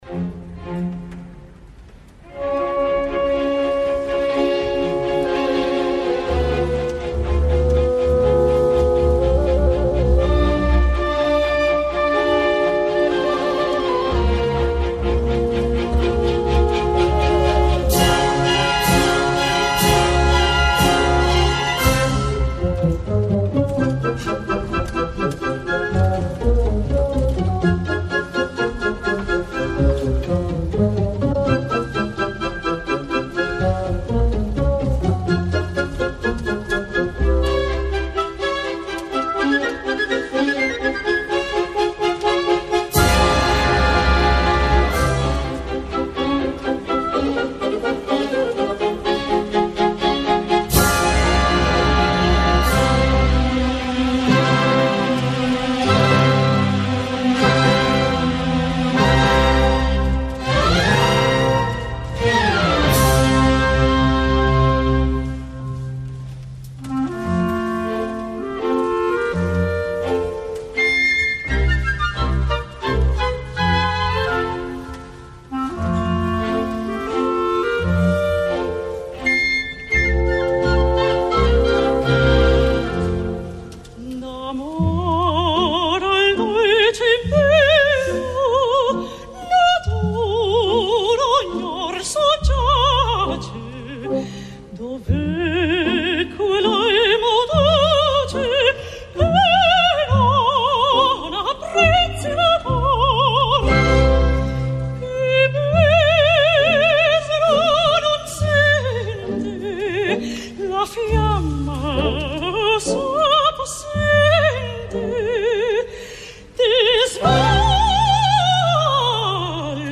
Va rebre alguna lleu però sonora protesta en acabar l’ària i la representació, ho entenc.
Musica di Gioachino Rossini
Orchestra e Coro del Teatro Comunale di Bologna
direttore Carlo Rizzi
Adriatic Arena de Pesaro, 10 d’agost de 2014